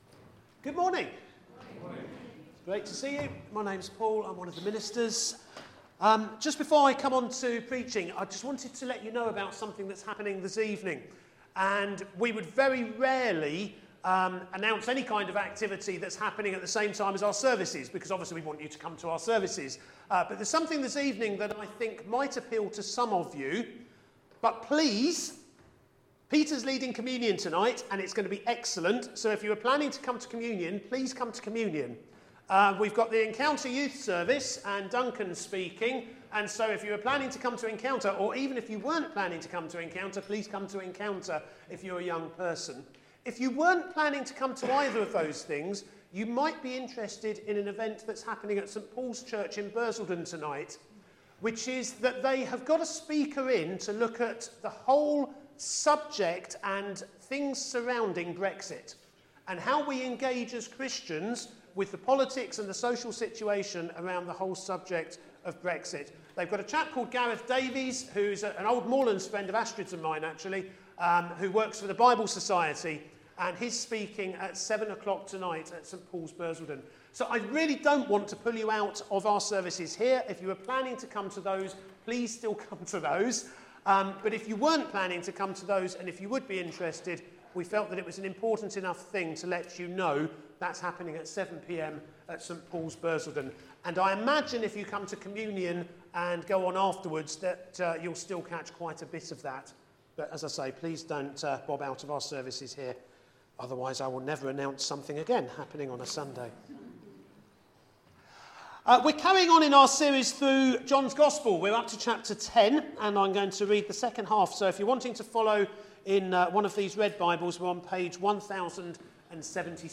A message from the series "God: in Person."